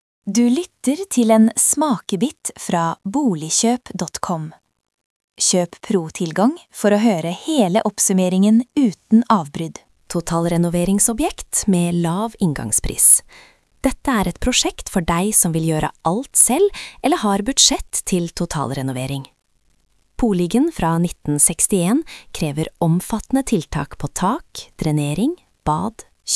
Lytt til AI-oppsummering av boligannonser | Nå kan du lytte til boliganalysen, helt gratis!
Det koster mer, men gir veldig bra kvalitet og stemmen høres nesten virkelig ut. lytt her